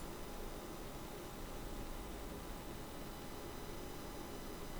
Record MU-75 microphone buzzing noise
I recently bought a MU-75 USB condenser microphone and the sound is amazing, but i have this annoying buzzing in the background
I think it’s just normal noise from the microphone’s internal preamp.
The noise level is a little high, but it’s not terrible (the average is around -47dB).
(I’d describe that as “hiss” rather than “buzz” or “hum”.)